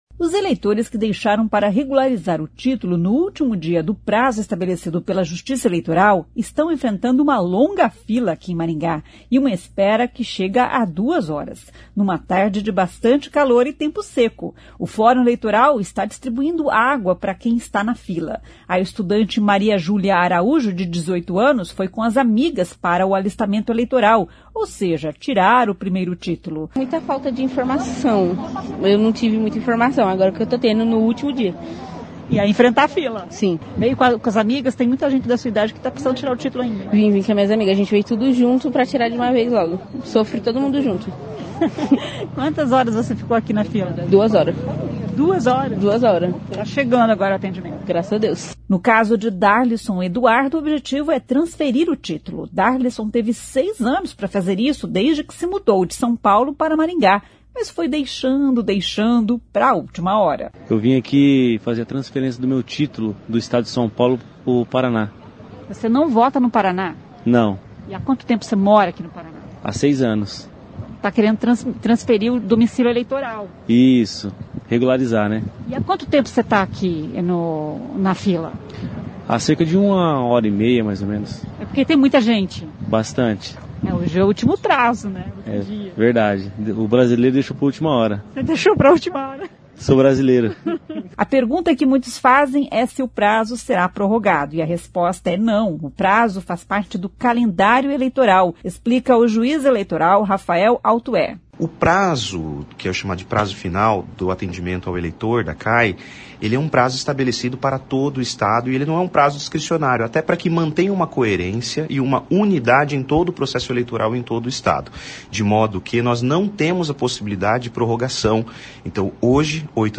O prazo faz parte do calendário eleitoral, explica o juiz eleitoral Rafael Altoé.